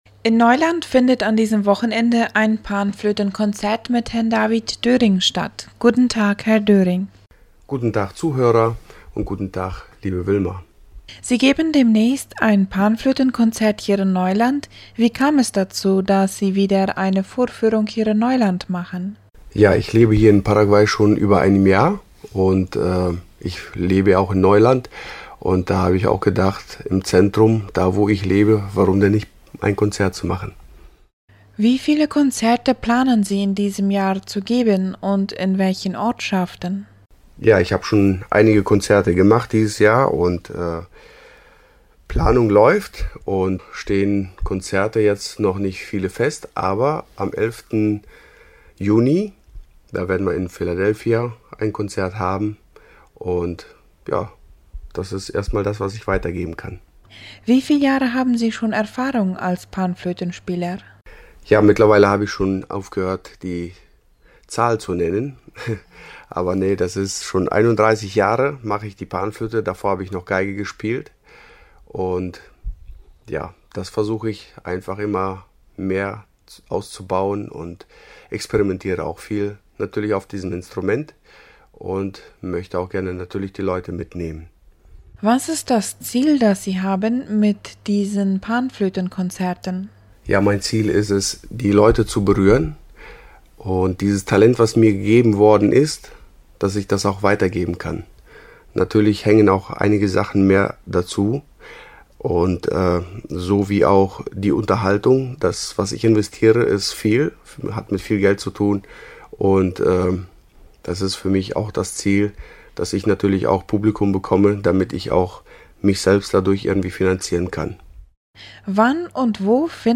2023-05-19_Panflötenkonzert